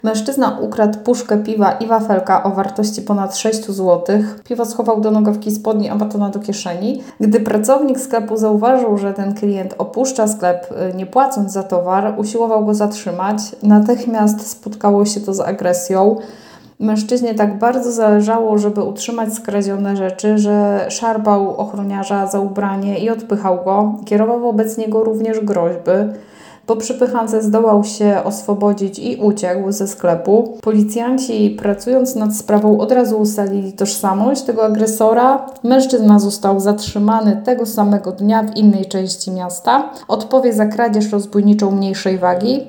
Mówiła Radiu 5